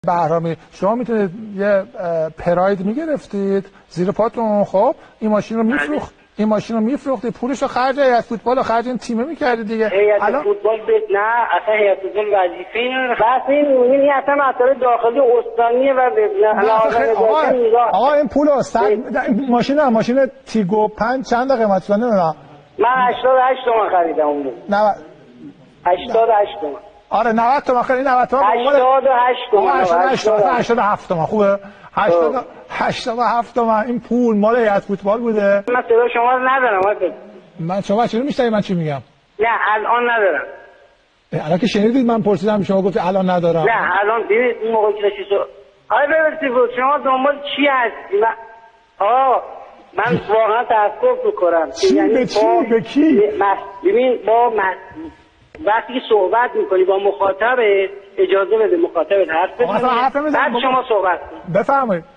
نسخه کامل صوتی برنامه نود